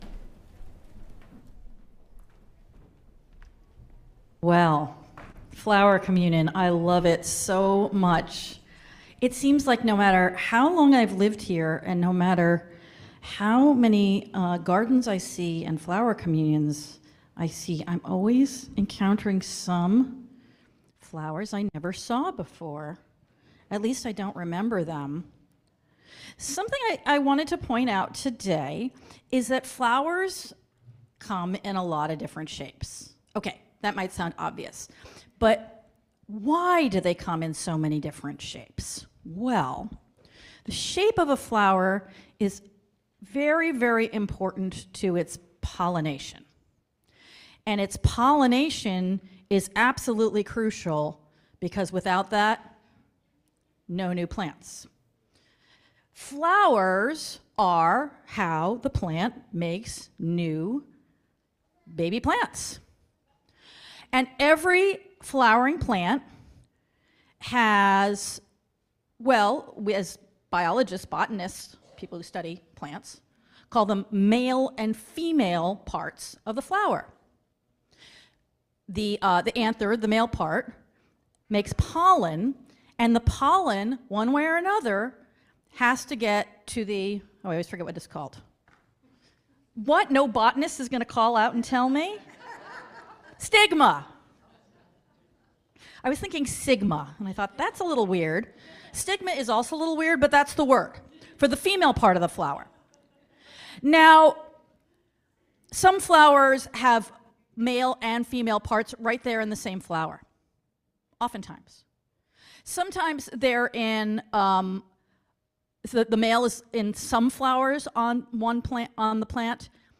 Speaker: